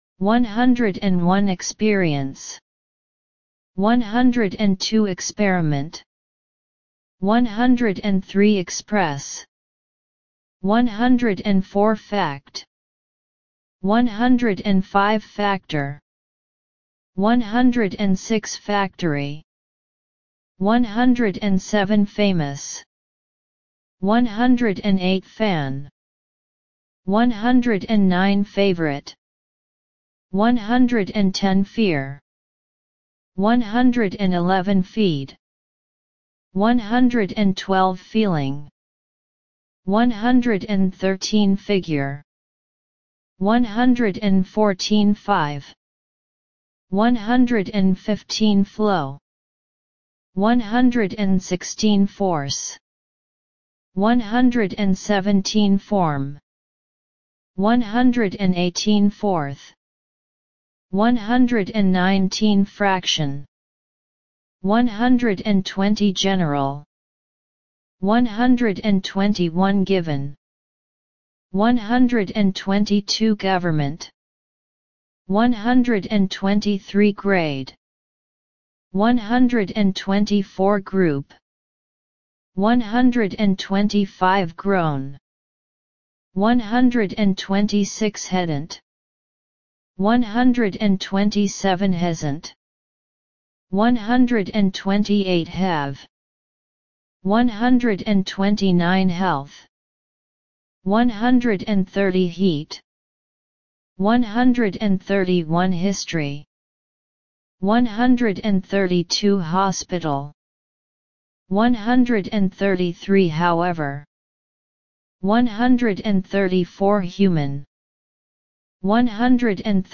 101 – 150 Listen and Repeat